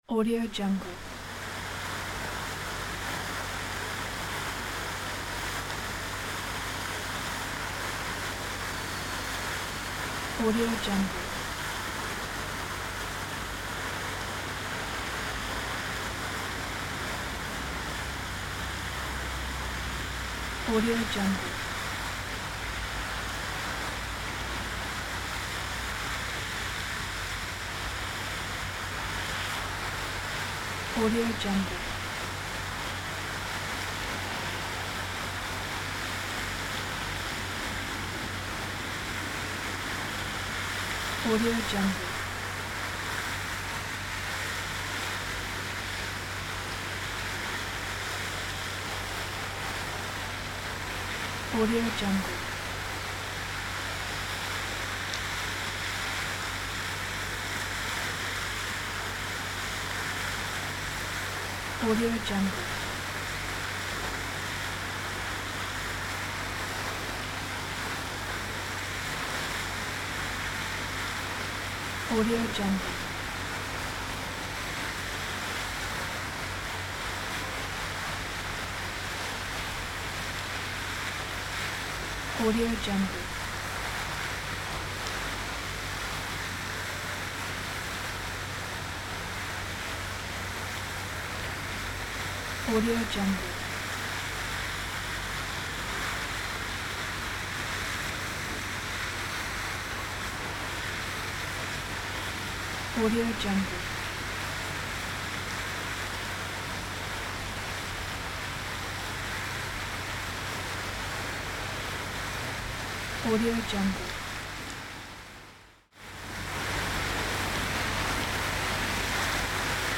دانلود افکت صدای فواره ی پارک
دانلود افکت صوتی شهری
• ایجاد جو آرامش‌بخش و دلنشین: صدای ملایم فواره آب در پارک، حس آرامش و سکون را به بیننده منتقل می‌کند و می‌تواند برای ایجاد جوهای رمانتیک، آرامش‌بخش یا حتی ماجراجویانه در ویدیوهای شما استفاده شود.
• کیفیت بالا: این فایل صوتی با کیفیت بالا ضبط شده است تا بهترین تجربه صوتی را برای شما فراهم کند.
Sample rate 16-Bit Stereo, 44.1 kHz